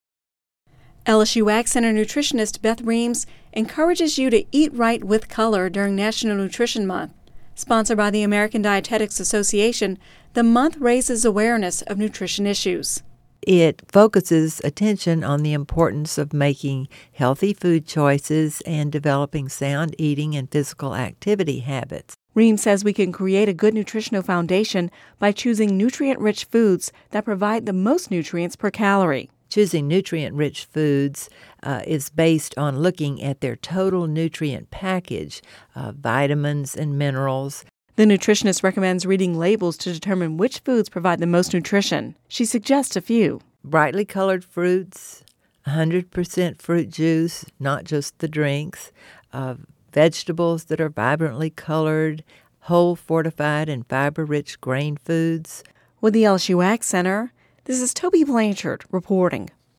(Radio News 03/07/11)